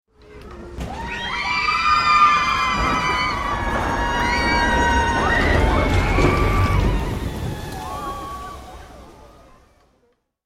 دانلود صدای جیغ مردم از ساعد نیوز با لینک مستقیم و کیفیت بالا
جلوه های صوتی